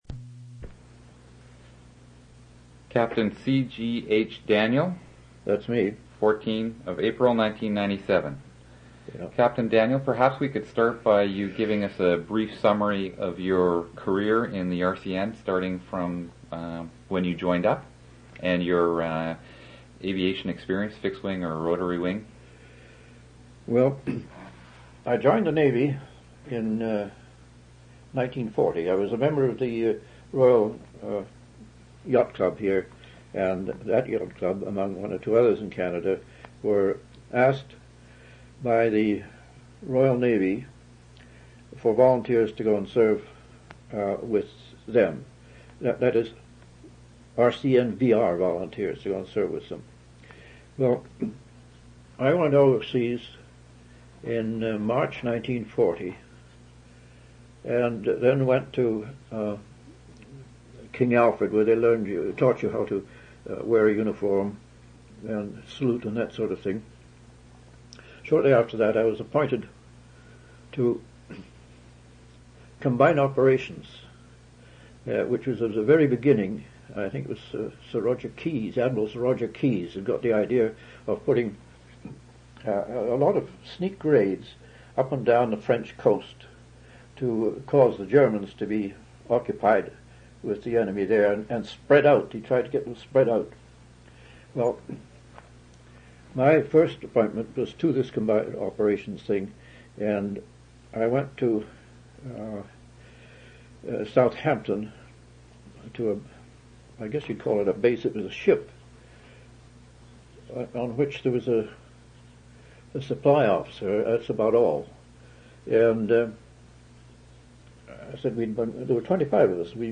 Personal narratives--Canadian Naval history
One original audio cassette in Special Collections.
oral histories (literary genre)